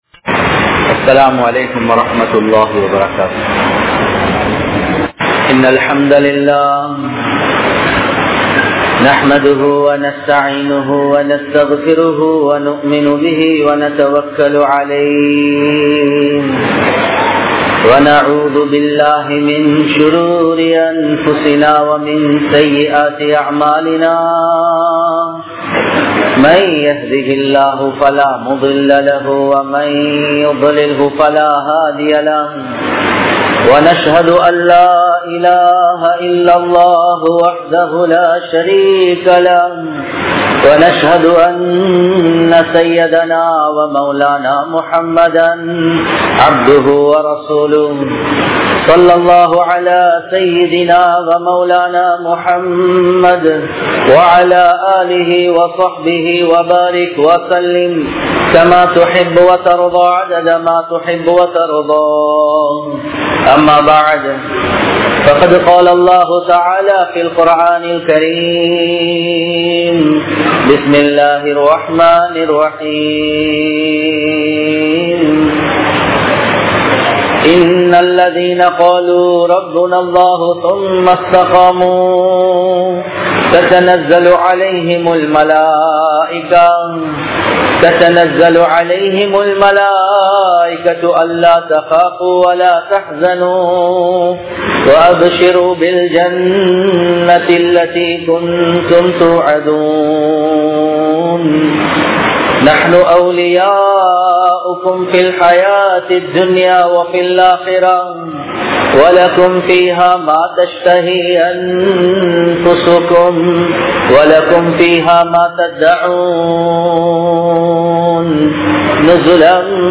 Ramalaanil mattum Muslimkal | Audio Bayans | All Ceylon Muslim Youth Community | Addalaichenai
Muhiyadeen Jumua Masjith